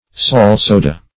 Salsoda \Sal`so"da\, n.
salsoda.mp3